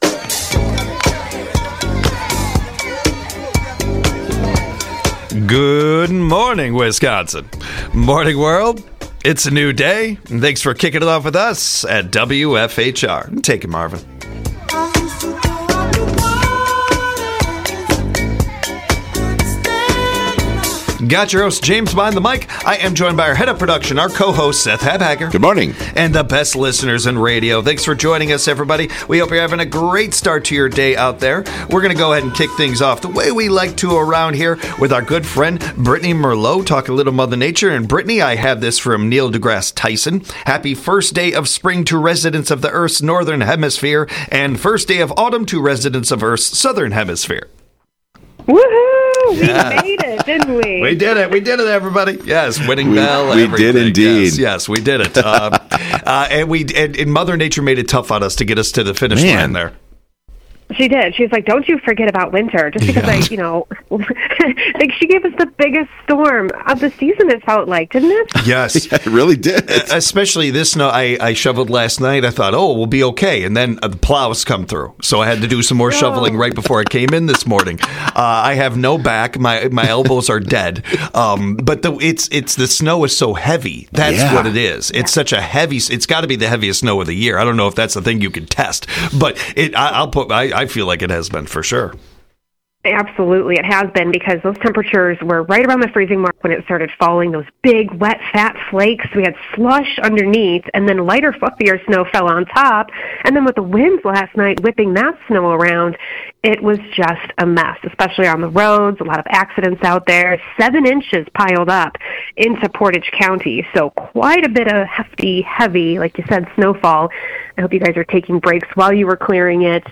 They cover some college sports with a caller They discuss some interesting offers